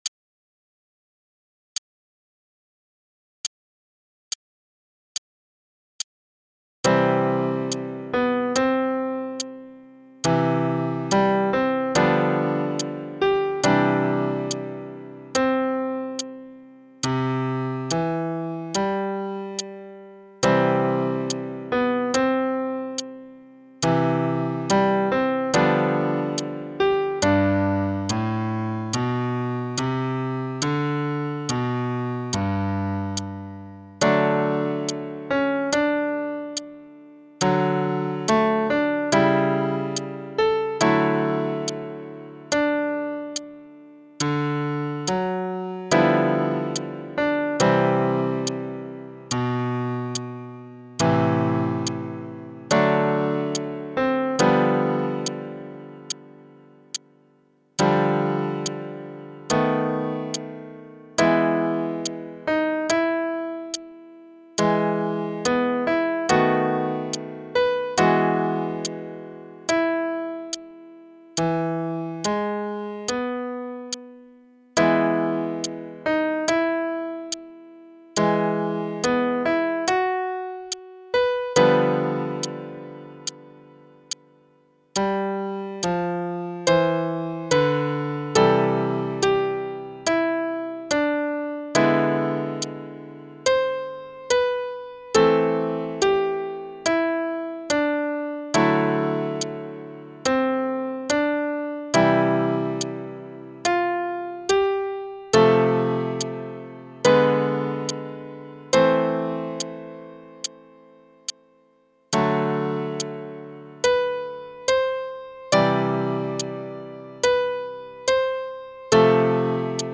backing track
qn=72))